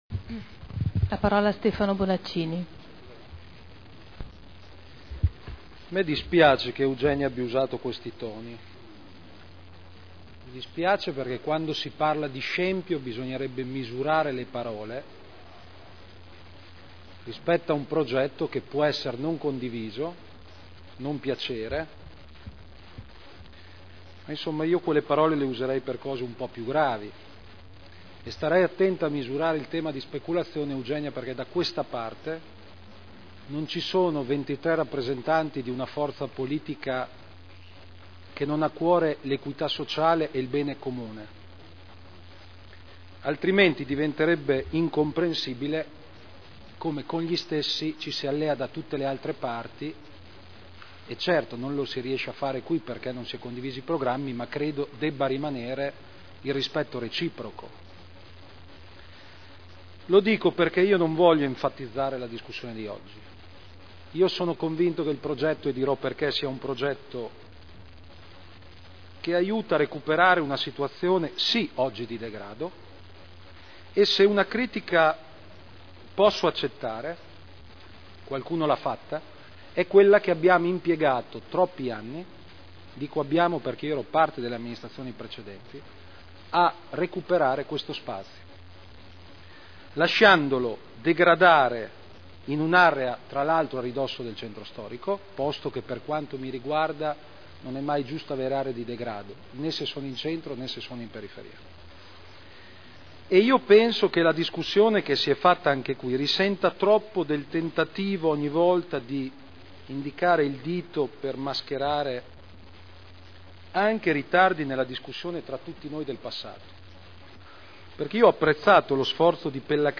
Stefano Bonaccini — Sito Audio Consiglio Comunale
Seduta del 21/12/2009. Piano Urbanistico Attuativo – Variante al Piano particolareggiato di iniziativa pubblica area ex sede A.M.C.M. in variante al POC – Controdeduzioni alle osservazioni e approvazione ai sensi dell’art. 35 della L.R. 20/2000 (Commissione consiliare dell’1 e 17 dicembre 2009)